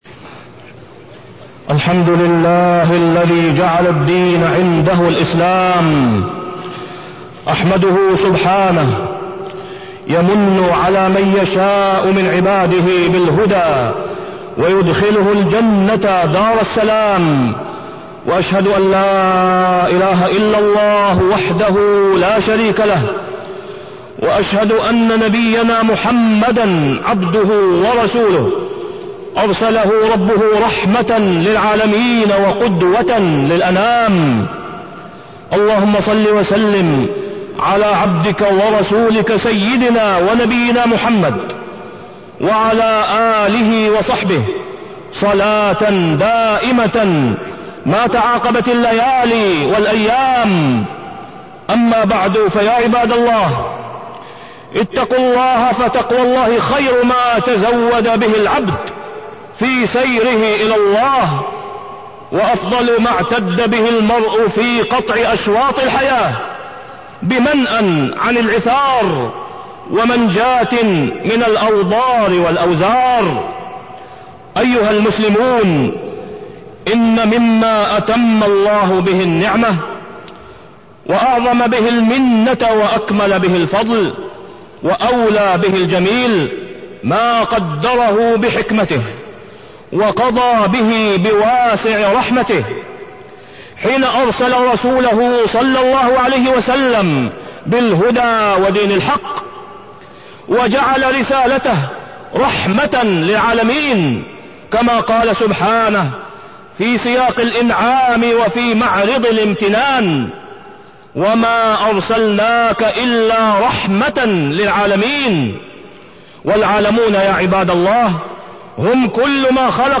تاريخ النشر ٢١ ذو القعدة ١٤٢٣ هـ المكان: المسجد الحرام الشيخ: فضيلة الشيخ د. أسامة بن عبدالله خياط فضيلة الشيخ د. أسامة بن عبدالله خياط الرحمة المهداة